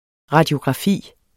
Udtale [ ʁɑdjogʁɑˈfiˀ ]